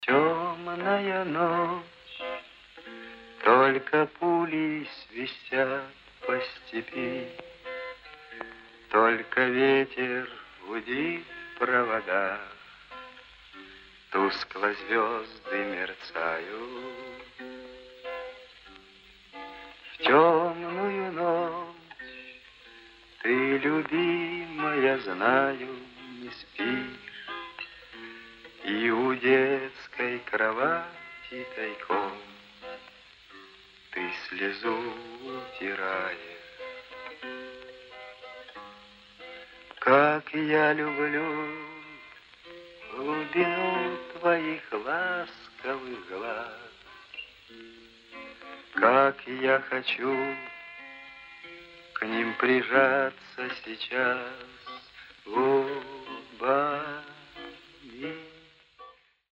Гитара
Мужской голос
Ретро